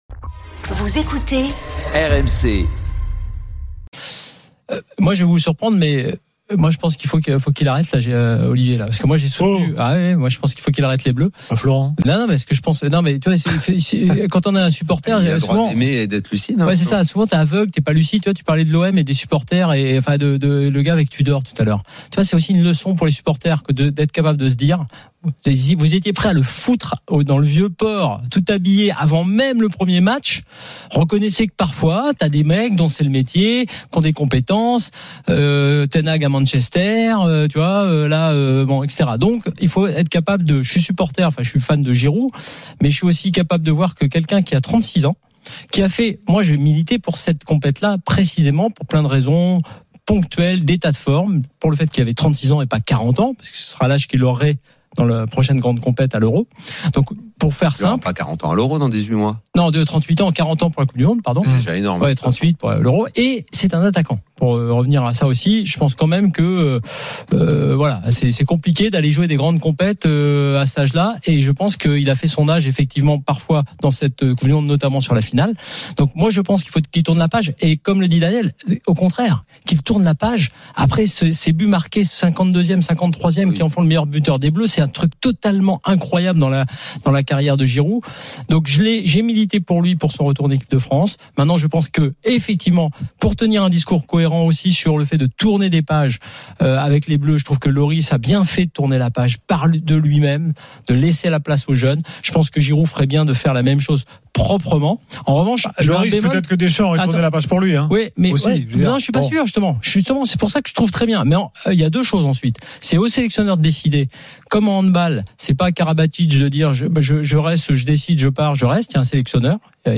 Chaque jour, écoutez le Best-of de l'Afterfoot, sur RMC la radio du Sport !
RMC est une radio généraliste, essentiellement axée sur l'actualité et sur l'interactivité avec les auditeurs, dans un format 100% parlé, inédit en France.